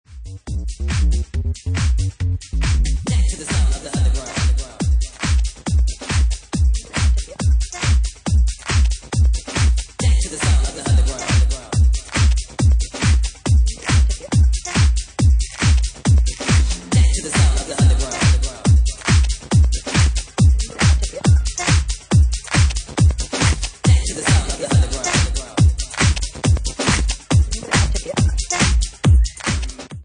Genre:Bassline House
140 bpm